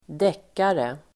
Uttal: [²d'ek:are]